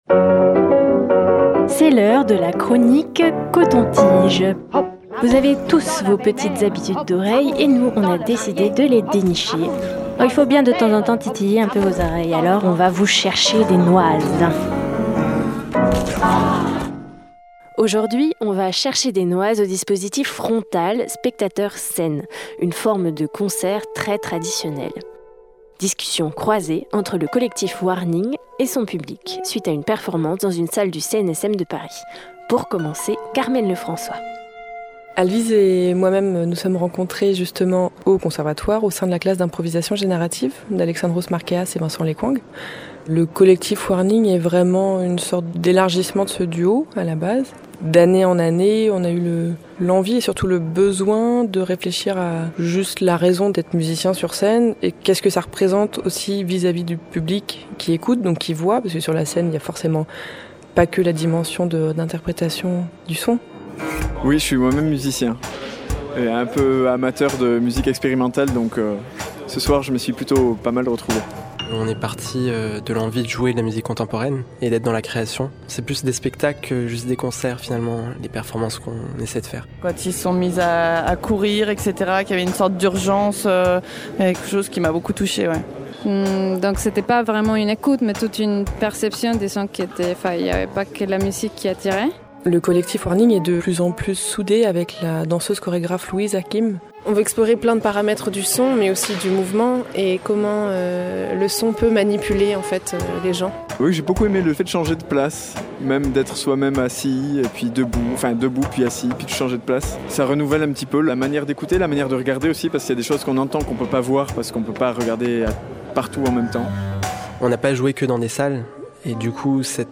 Elle propose "une écoute ludique, plus engagée" selon le public composé en majorité de musiciens que nous avons interrogé à la suite de l'une de leurs performances.